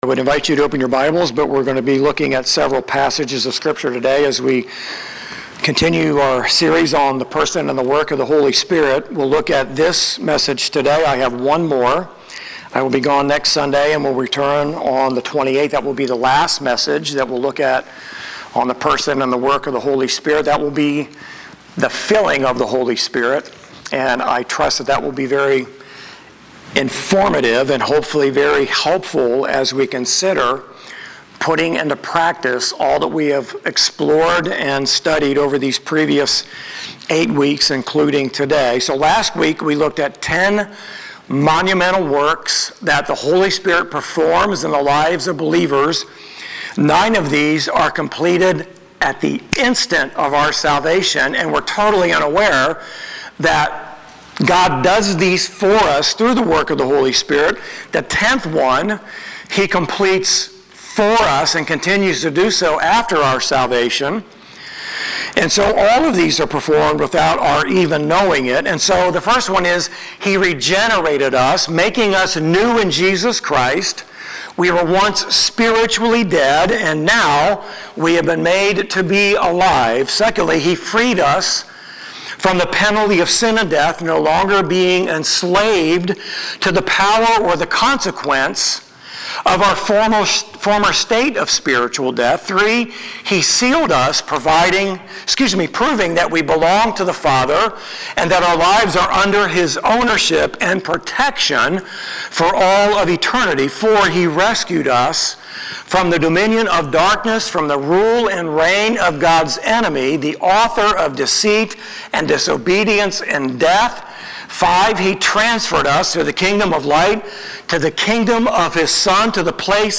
9-14-25-Sermon.mp3